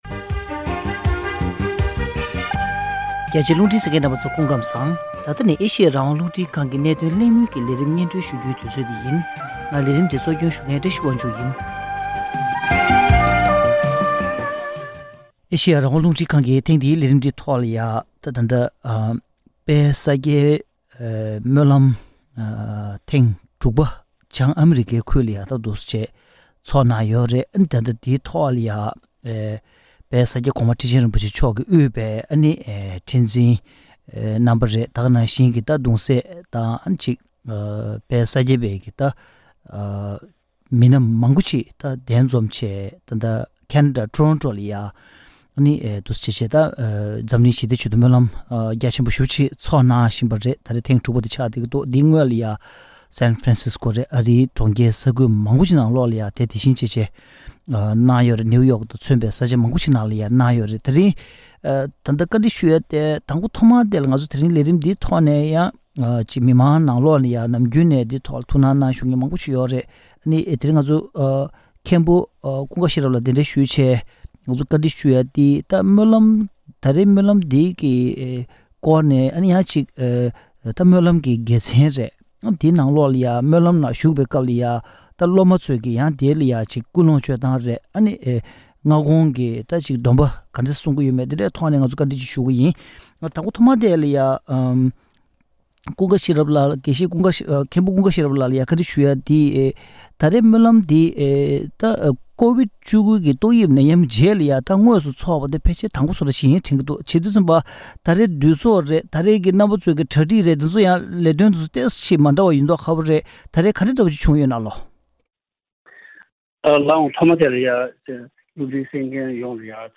གནད་དོན་གླེང་མོལ་གྱི་ལས་རིམ་ནང་།